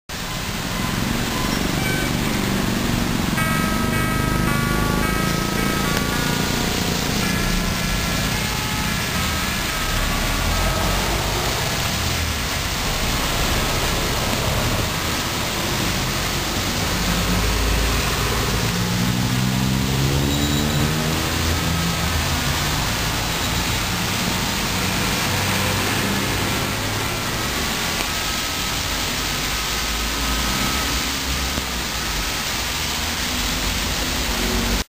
雨の中で、収録中に車が通るたびにシャーという音が入るため実際行きたくないながらも行きましたが、結構２１号以外がありました。
次は日本信号２１７号「版１」です。